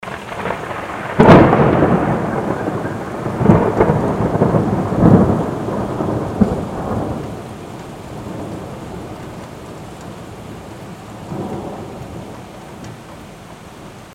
zvuk-groma_002
zvuk-groma_002.mp3